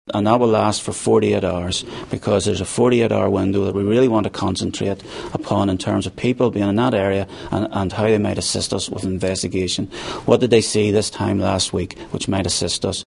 Deputy Chief Constable Drew Harris says it’s important to jog peoples’ memories of what they may have seen…….